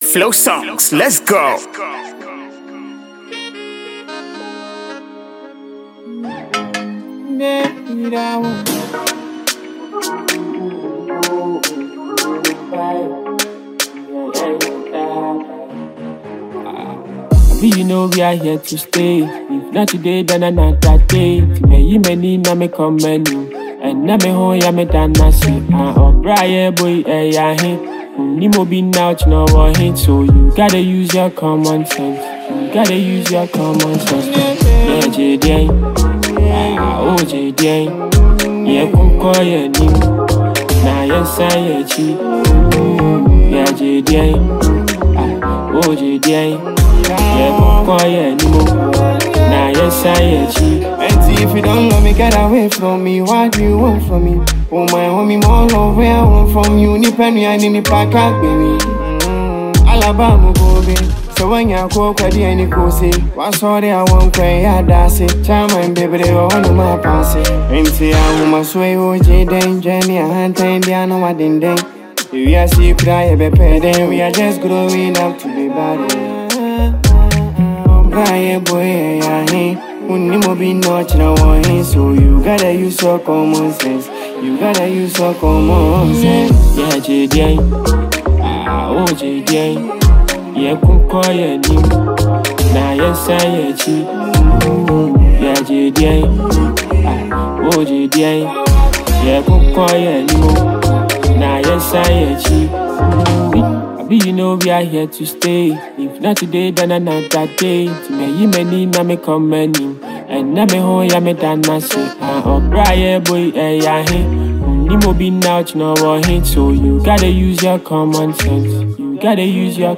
It is a catchy and lively melody.
AFROPOP and Afrobeat